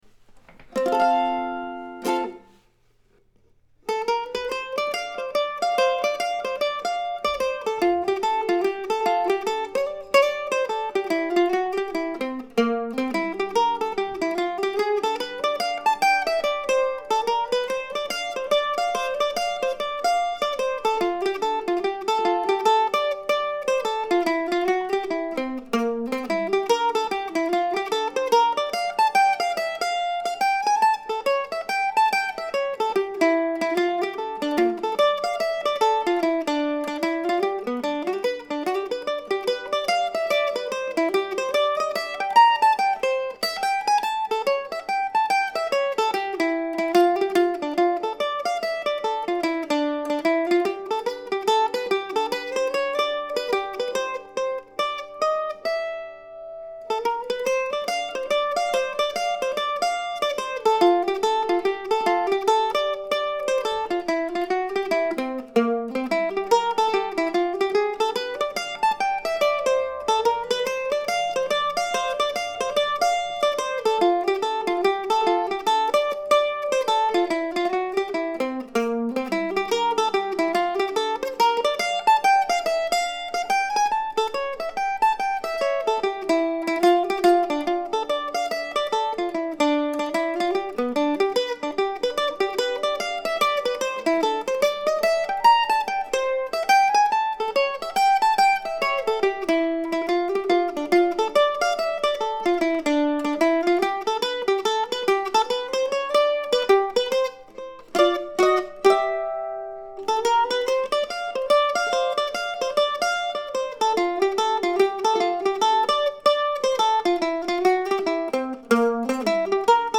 The recording is a no frills statement of the melody on the mandolin without accompaniment.